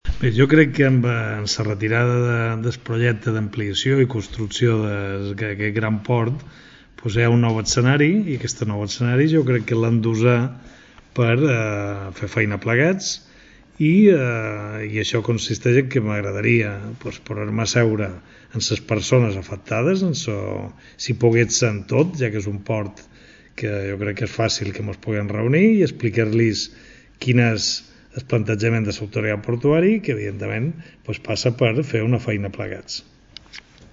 Declas Gual convocant els socis CM Molinar.MP3